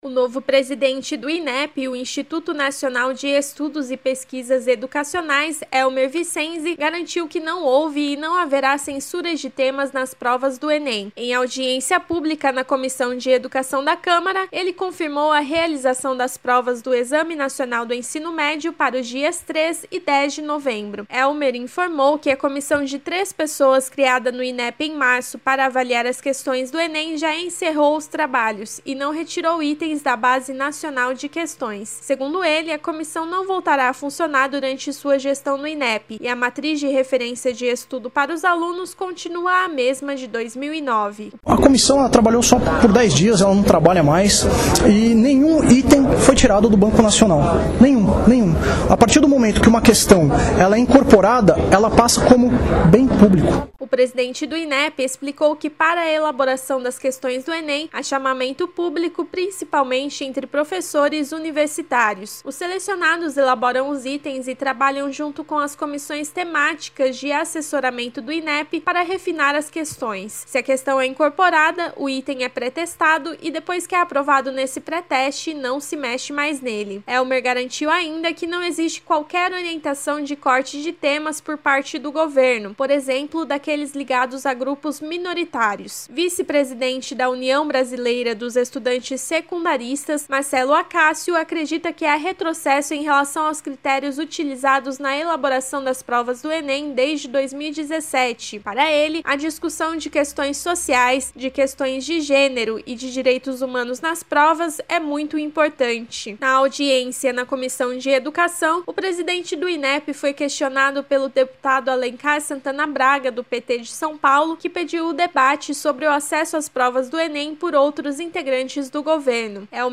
Em audiência pública na Comissão de Educação da Câmara, ele confirmou a realização das provas do Exame Nacional do Ensino Médio para os dias 3 e 10 de novembro.